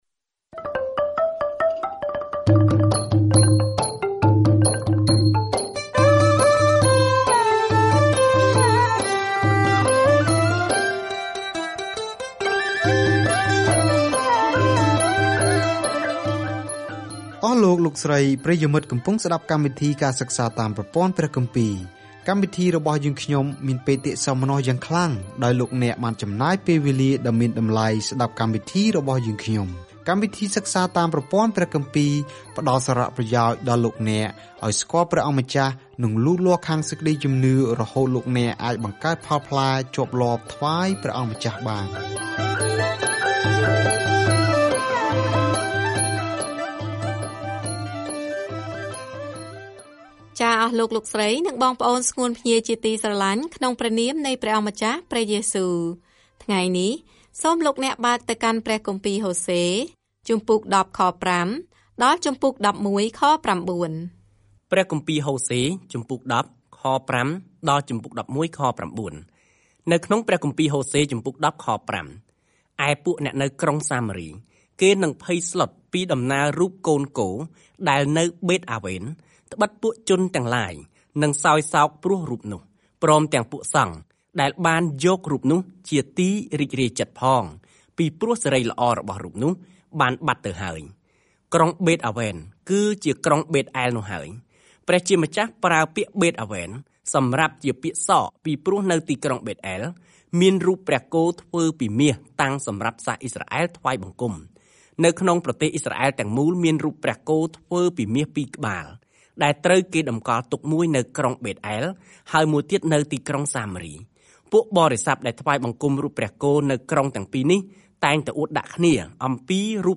ព្រះបានប្រើអាពាហ៍ពិពាហ៍ដ៏ឈឺចាប់របស់ហូសេជាការបង្ហាញពីអារម្មណ៍របស់ទ្រង់ពេលរាស្ដ្ររបស់ទ្រង់មិនស្មោះត្រង់នឹងទ្រង់ ប៉ុន្តែទ្រង់ប្ដេជ្ញាថានឹងនៅតែស្រឡាញ់ពួកគេ ។ ការធ្វើដំណើរជារៀងរាល់ថ្ងៃតាមរយៈហូសេ ពេលអ្នកស្តាប់ការសិក្សាជាសំឡេង ហើយអានខគម្ពីរដែលជ្រើសរើសពីព្រះបន្ទូលរបស់ព្រះ។